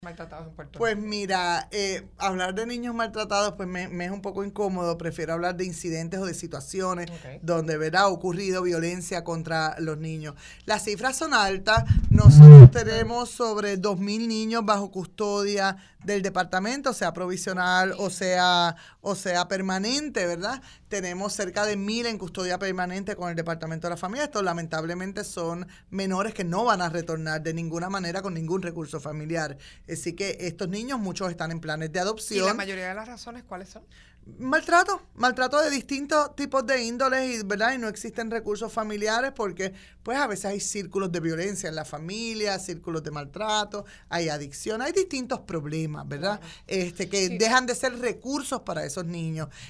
La secretaria del Departamento de la Familia, Suzanne Roig indicó en El Calentón que padres de casi mil menores en Puerto Rico han perdido custodia de sus hijos por maltrato.